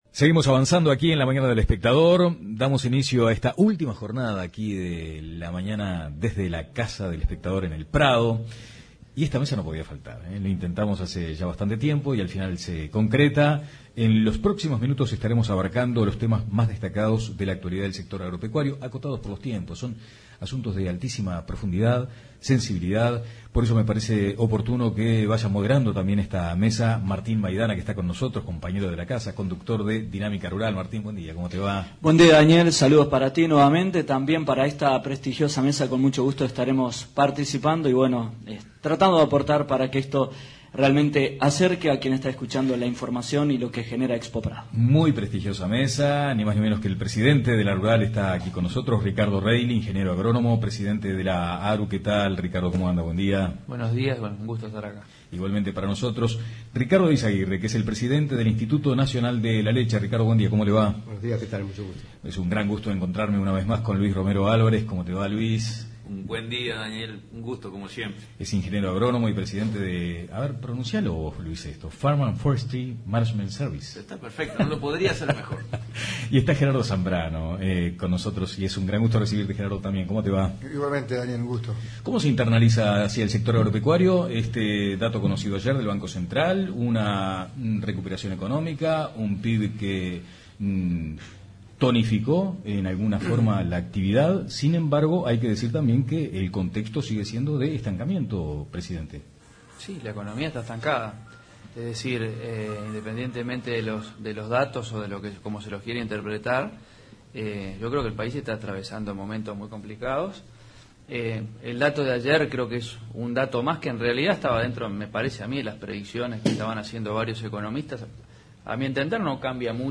Tertulia especial desde la Expo Prado: situación de Venezuela. ¿Sector lechero en crisis?
En esta Tertulia especial nos rodeamos de especialistas del sector agropecuario.